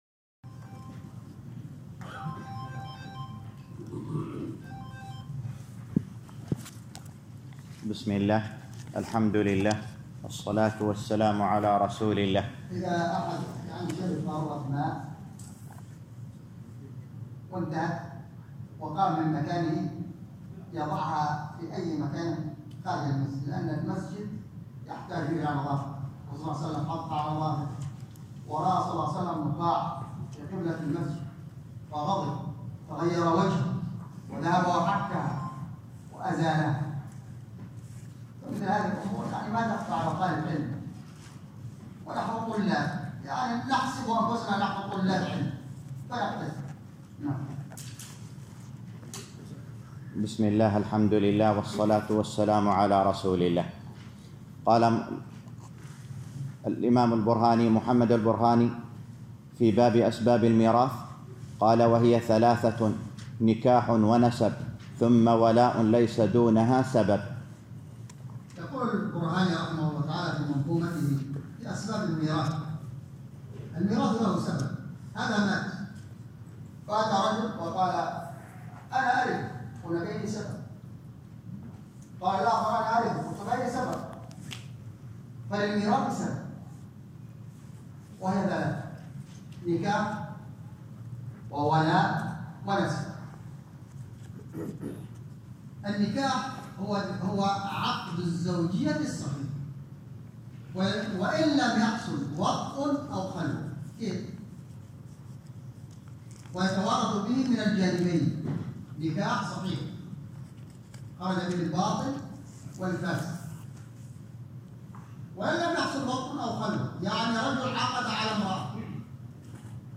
معلومات الملف ينتمي إلى: شرح المنظومة البرهانية في الفرائض (الشرح الجديد) الدرس الرابع - شرح المنظومة البرهانية في الفرائض _ 4 مشاركة عبر واتس آب مشاركة عبر تيليجرام test An html5-capable browser is required to play this audio.